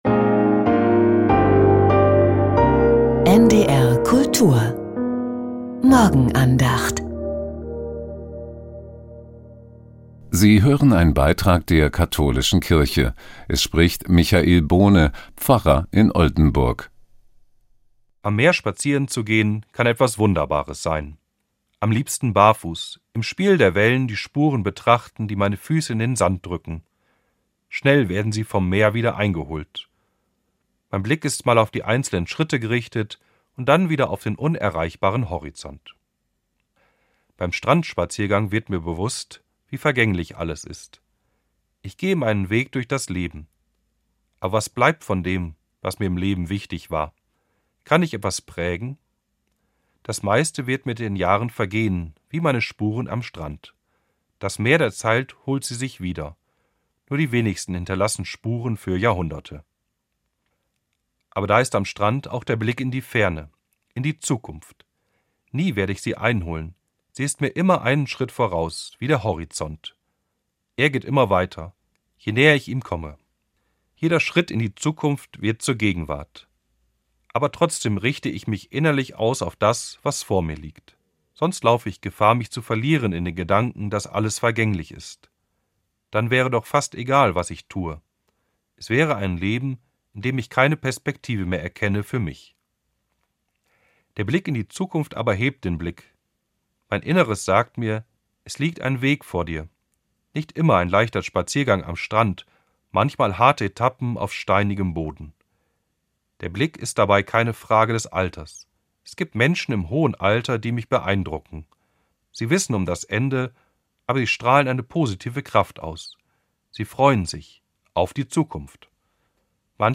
Die Morgenandacht von